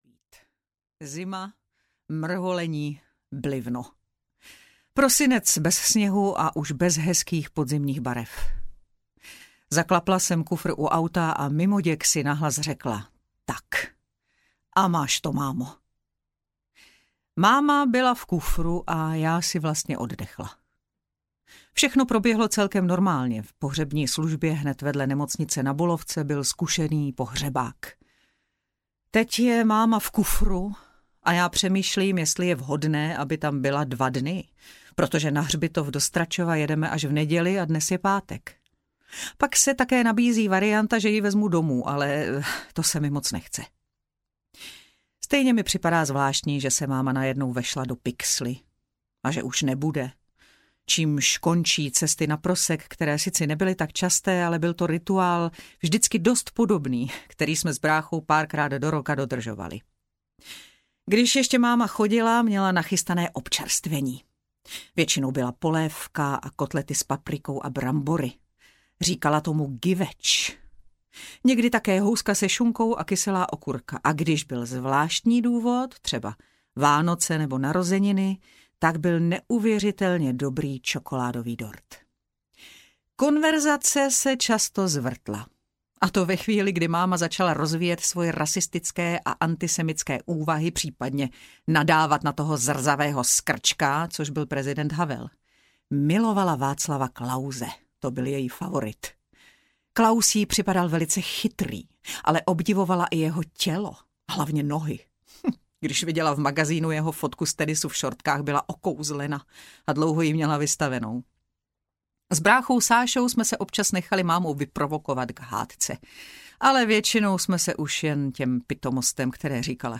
Poslední prvotina audiokniha
Ukázka z knihy
• InterpretBára Munzarová